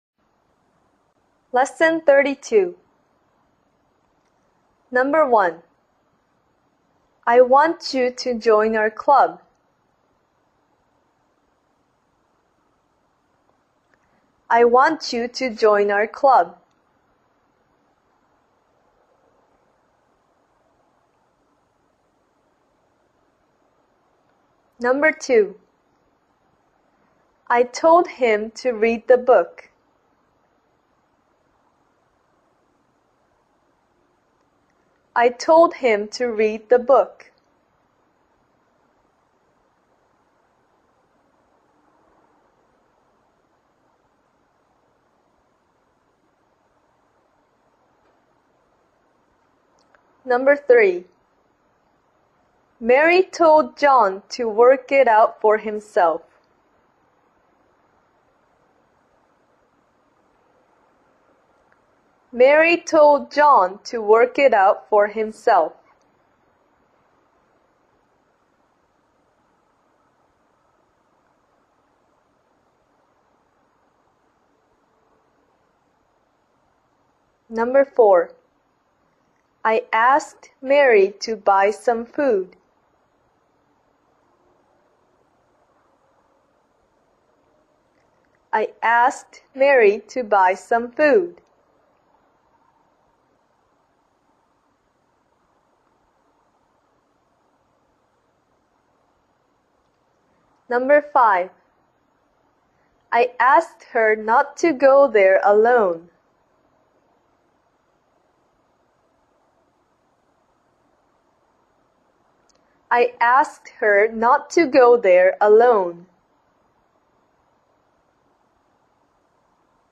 書籍「中学・高校６年間の英文法が21日で身につく」ご購入の方のためのネイティブ音声ダウンロードページです。